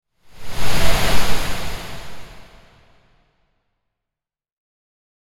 Soft-wind-swish-whoosh-sound-effect.mp3